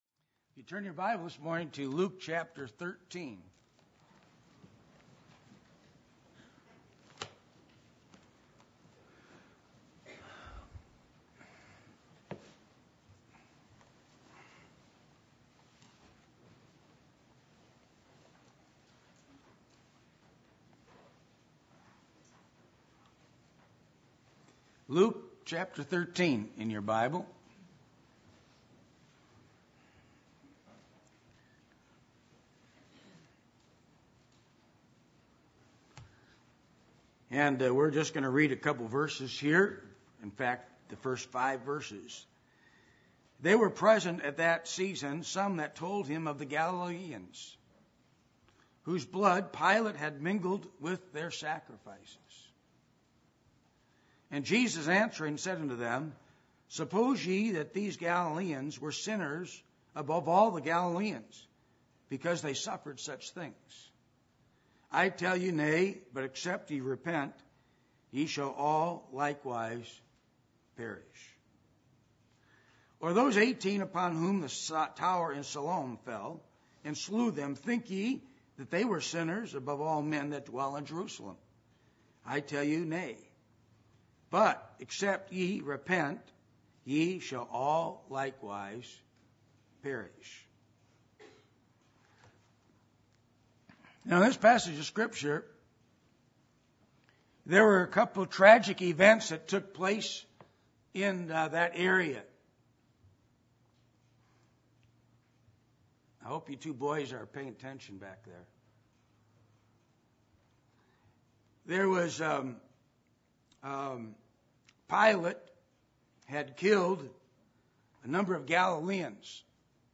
Acts 3:19 Service Type: Sunday Morning %todo_render% « The Attributes Of God